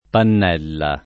[ pann $ lla ]